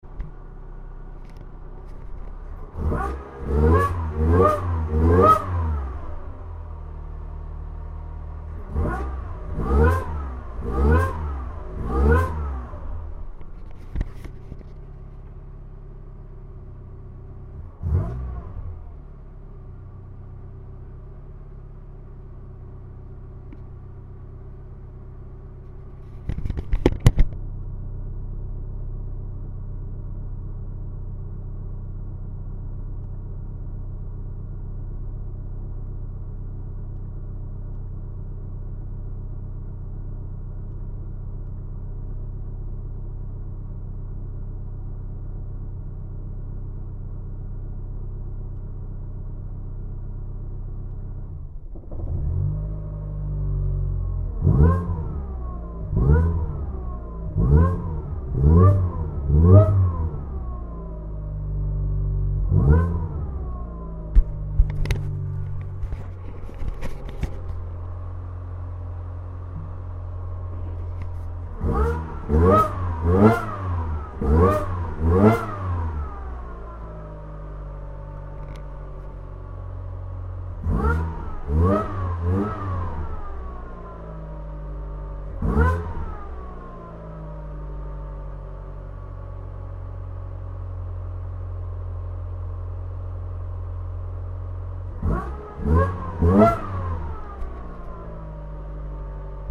Acceleration enrichment -> OK, la voiture prends bien des tours maintenant quand j'accelere franchement :faim: :woo: :woo: :woo: :woo:
Sinon, petite prise de son du soir, j'ai fait joujou rapidos pour tester l'acceleration enrichment